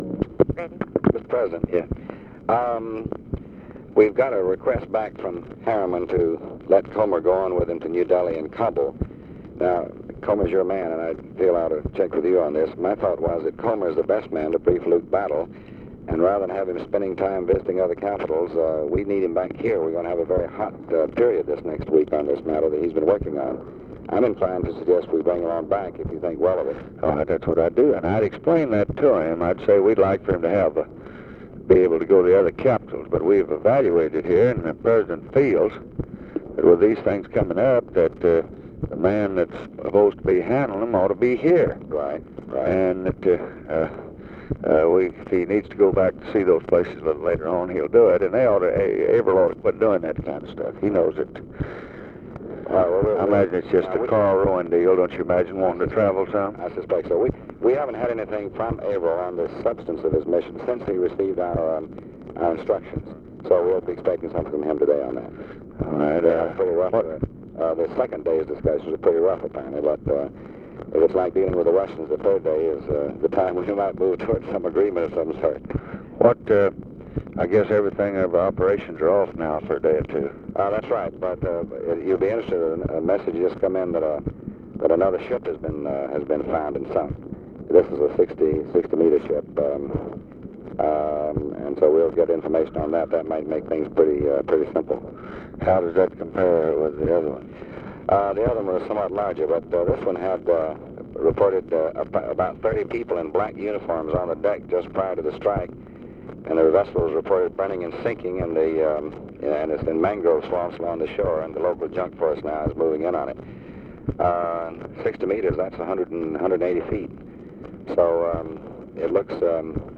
Conversation with DEAN RUSK, February 27, 1965
Secret White House Tapes